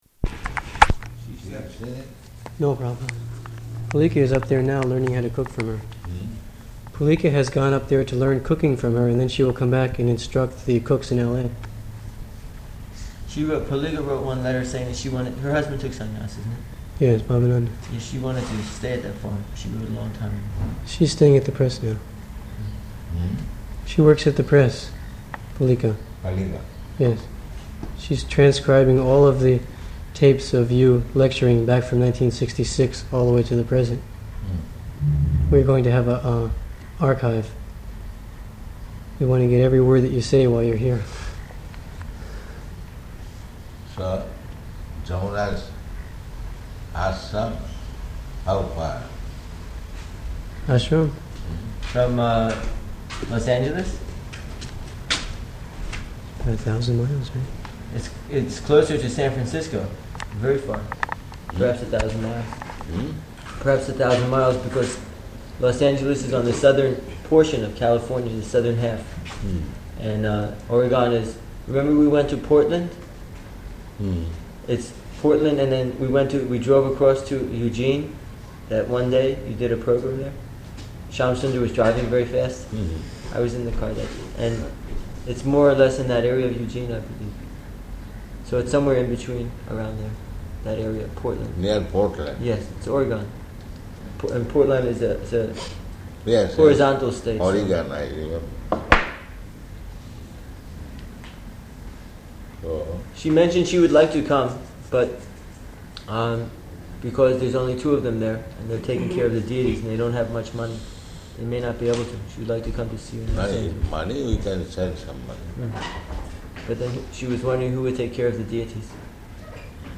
Room Conversation
Room Conversation --:-- --:-- Type: Conversation Dated: May 15th 1976 Location: Honolulu Audio file: 760515R1.HON.mp3 Prabhupāda: ...she still upstairs?